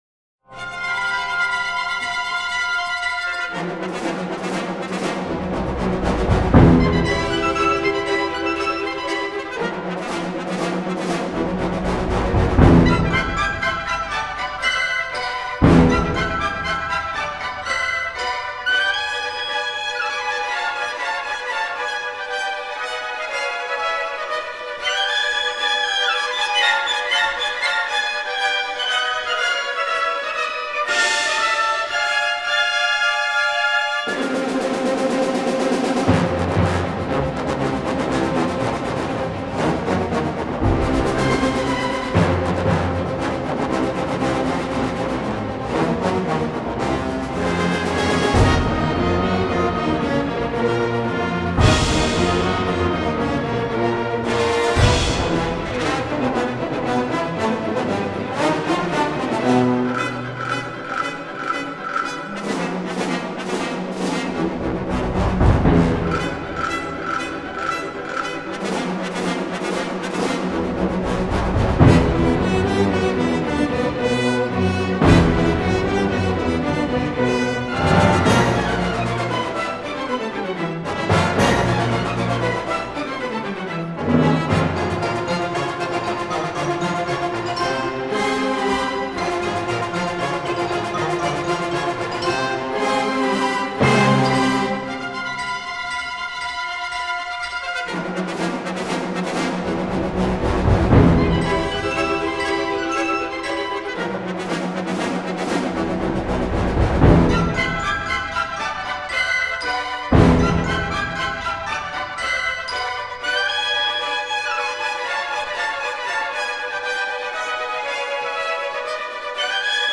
Emoties: boos
emoties-boos.mp3